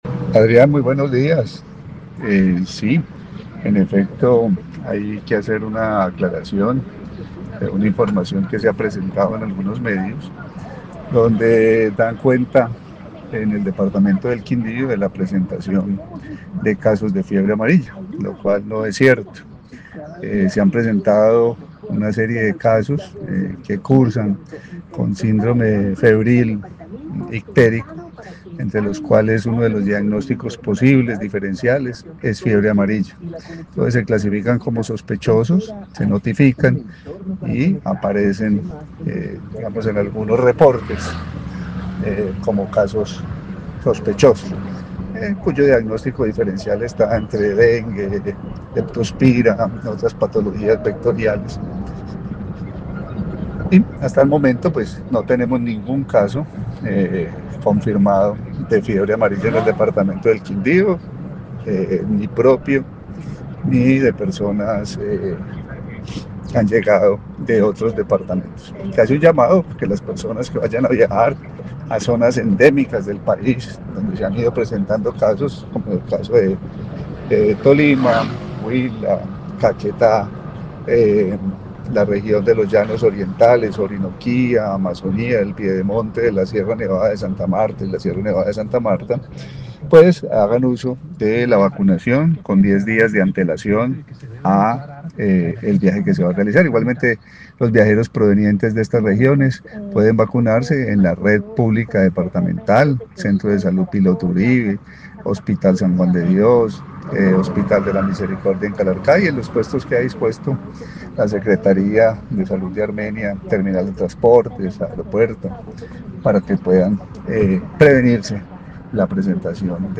Carlos Alberto Chacón, secretario de salud del Quindío
En Caracol Radio Armenia hablamos con el secretario departamental de salud, Carlos Alberto Gómez Chacón que aclaró que en la región no hay casos confirmados de fiebre amarilla.